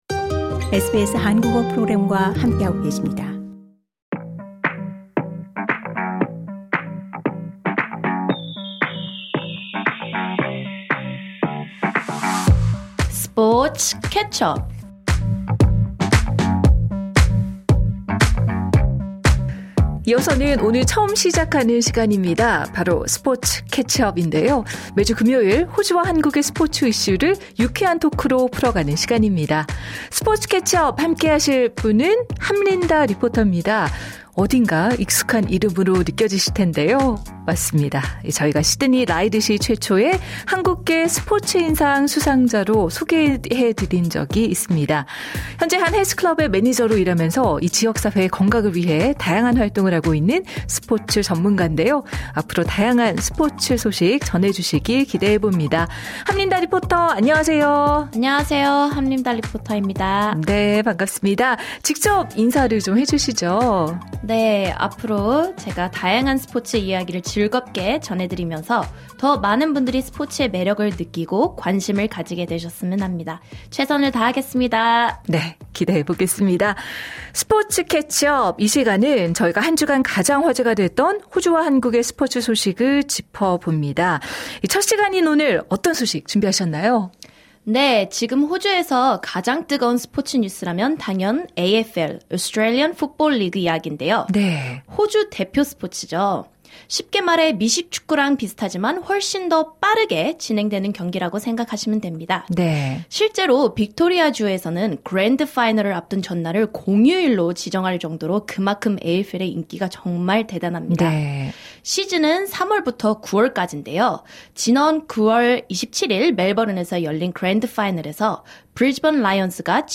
매주 호주와 한국의 스포츠 소식을 유쾌한 토크로 풀어냅니다. 오늘은 라이온스의 AFL 우승으로 열광 중인 브리즈번의 현장을 살펴보고, 오늘 밤 열리는 한국-브라질 친선 축구 경기와내일 예정된 호주-캐나다 친선 경기 소식도 전해드립니다.